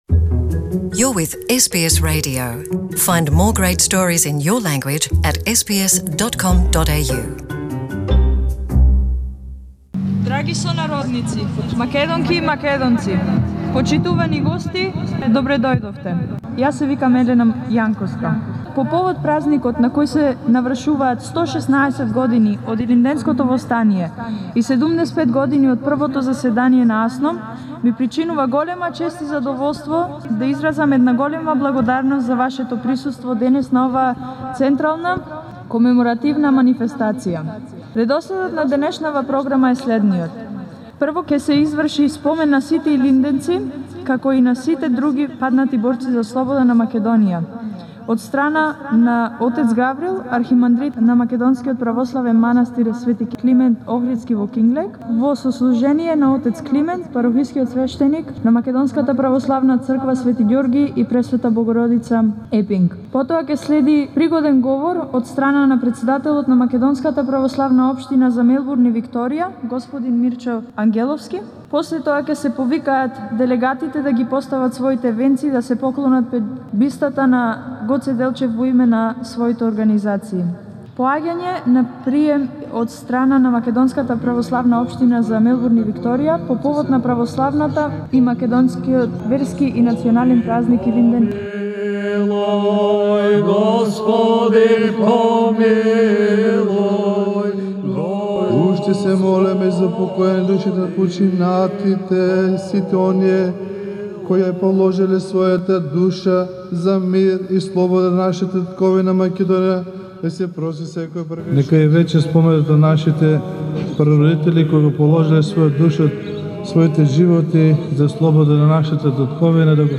SBS Macedonian follows the commemorative proceedings in honour of Ilinden organised by the Macedonian Orthodox Community of Melbourne and Victoria, St. George and Mother Mary, Epping on 4 August.